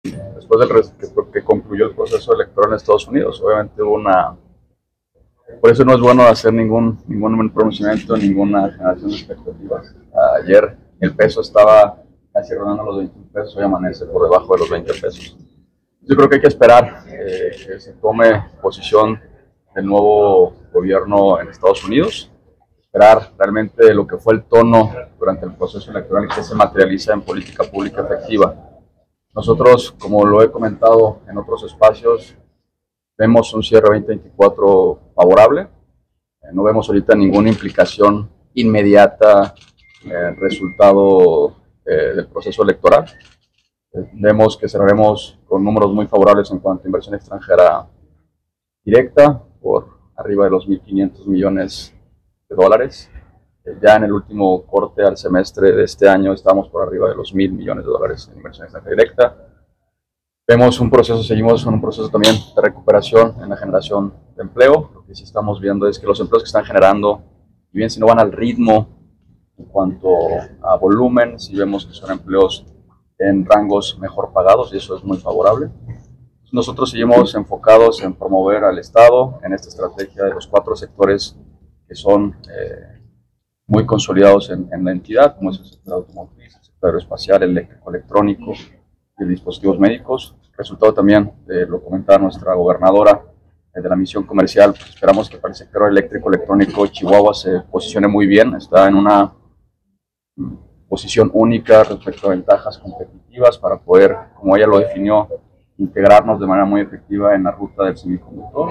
AUDIO: ULISES ALEJANDRO FERNÁNDEZ, SECRETARIO DE INNOVACIÓN Y DESARROLLO ECONÓMICO (SIDE)